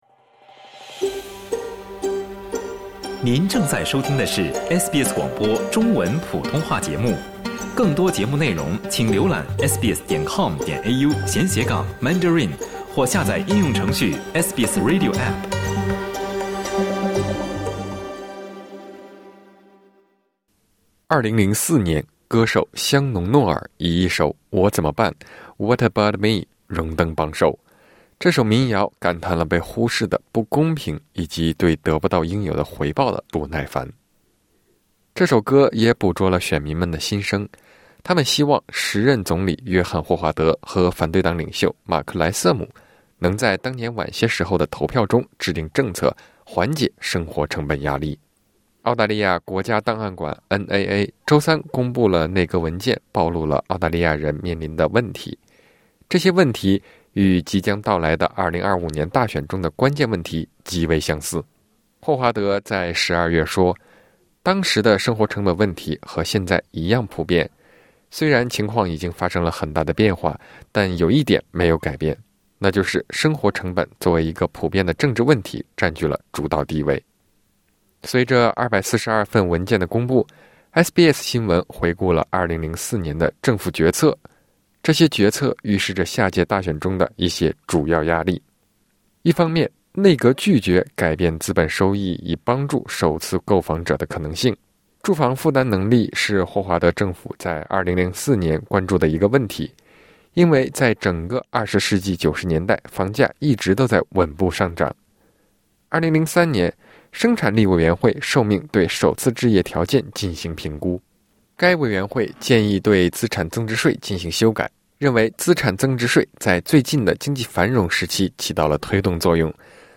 最新公布的政府内阁文件显示，二十年前，政府未能充分解决人们对超市行为和首次购房者压力的担忧。点击音频收听报道